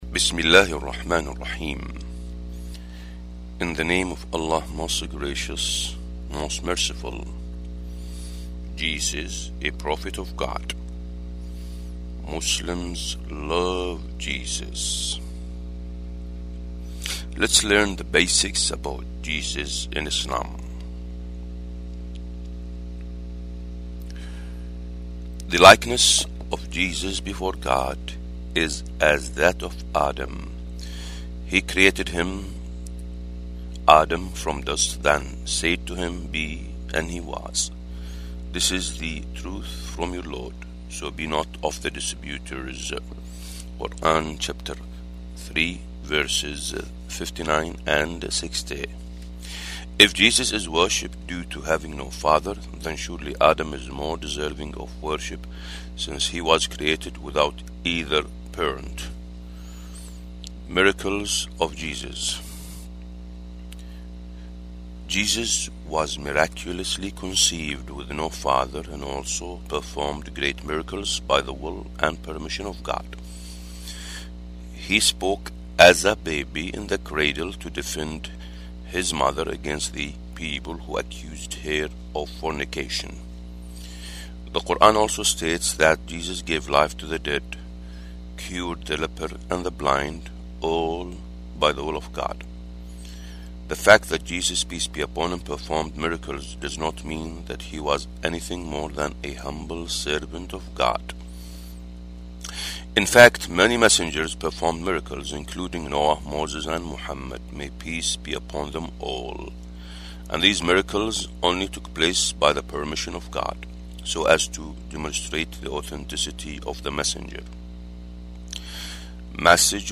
نبذة مختصرة: قراءة صوتية لرسالة موجهة لغير المسلمين باللغة الإنجليزية، وفيها بيان أن عيسي - عليه السلام - نبي ورسول، وقد أتى بما جاء به الأنبياء والمرسلون من قبله، وهو: الدعوة إلى توحيد الله - عز وجل -، وبشر برسول يأتي من بعده، وهو: محمد - صلى الله عليه وسلم -، ودعا إلى الإيمان والتصديق به لمن أراد النجاة.